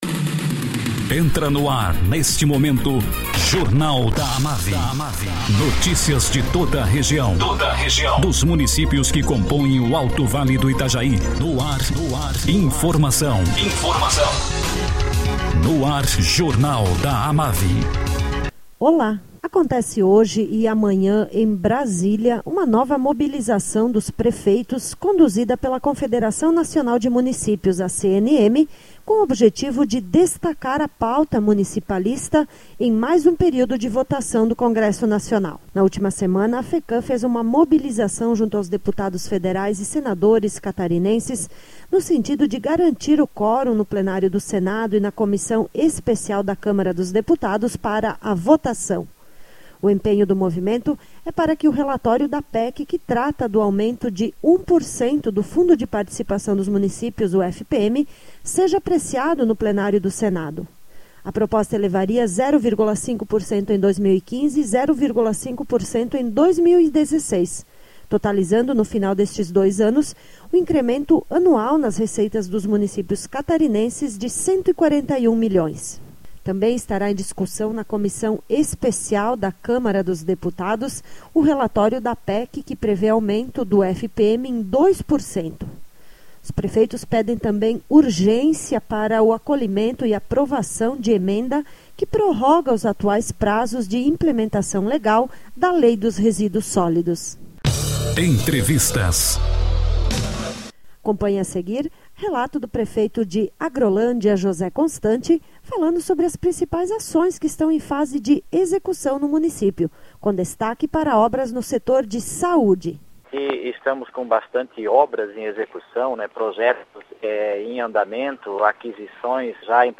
Prefeito de Agrolândia, José Constante, fala sobre obras que estão sendo realizadas no município na área da saúde e projetos para os próximos meses.